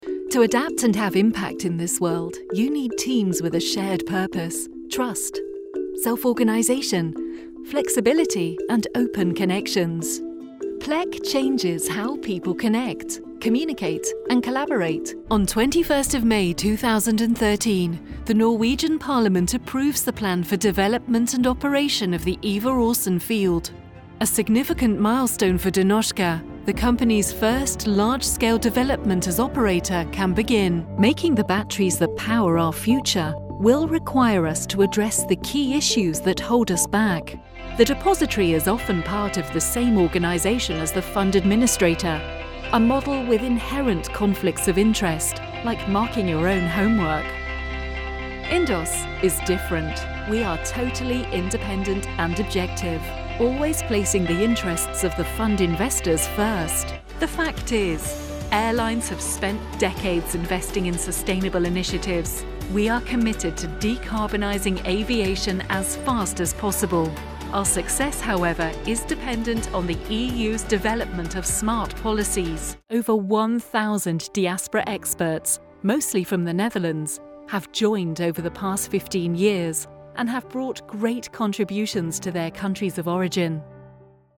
Warm english voice with a cool style for commercials, corporate, voice of god, explainer, documentary and more.
Highly experienced with own broadcast quality studio
britisch
Sprechprobe: Industrie (Muttersprache):
"Game changing" voiceovers.. high quality, hassle free.. warm voice.. cool vibes for ads, corporates and more